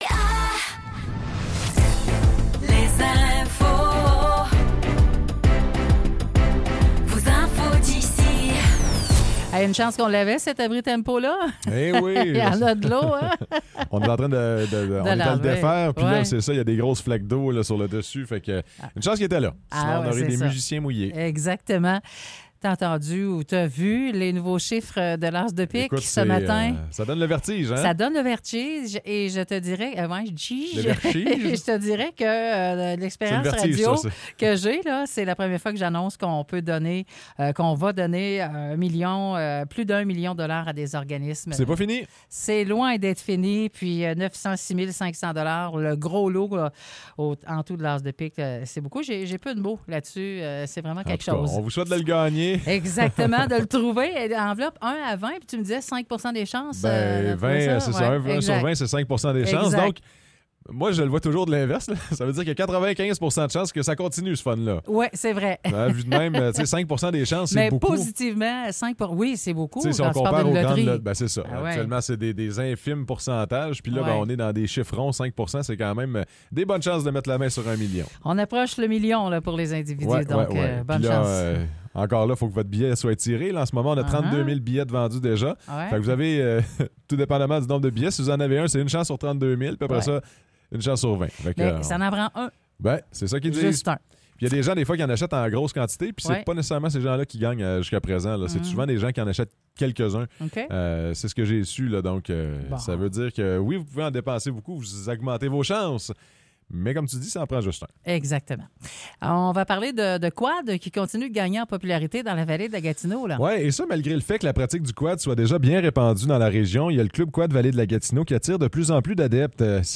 Nouvelles locales - 22 avril 2024 - 10 h